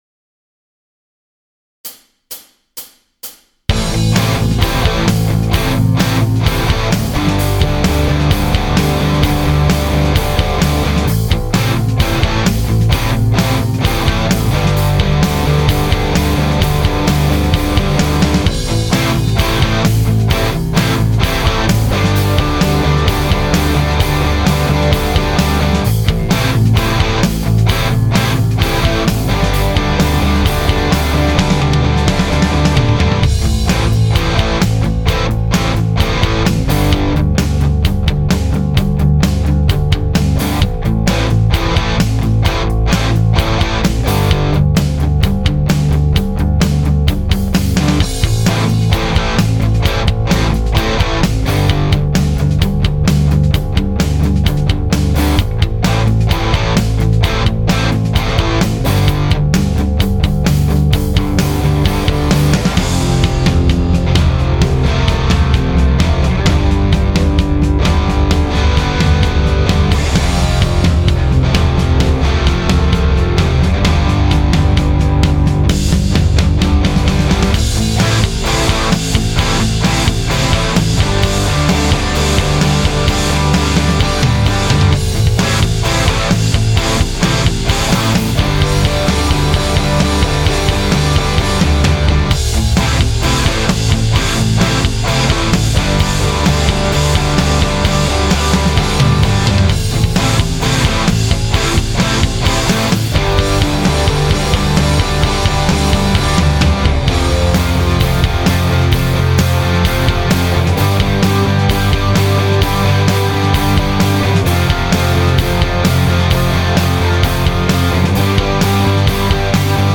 [Hardrock] Song im Aufbau
Schlagzeug ist aus der Dose und nur Platzhalter. Mix ist auch nur provisorisch.
Mir gefällt dieses Riff gut genug, um durchs Lied zu tragen.